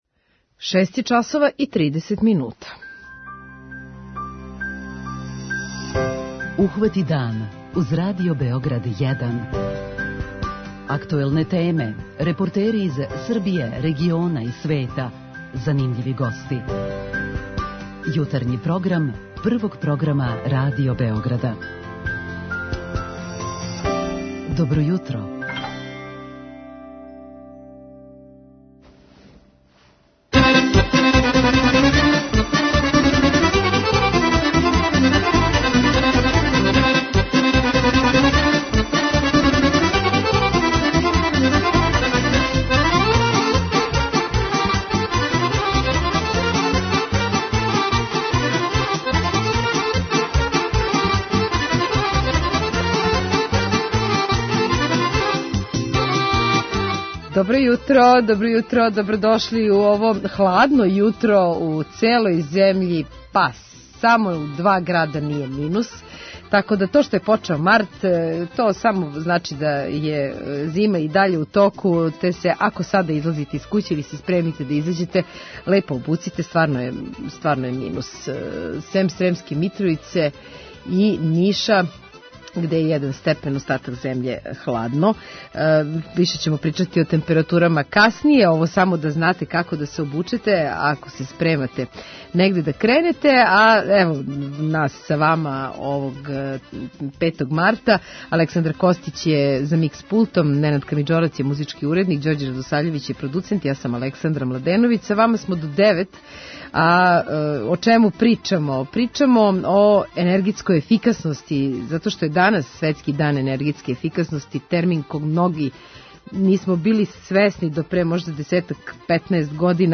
преузми : 26.98 MB Ухвати дан Autor: Група аутора Јутарњи програм Радио Београда 1!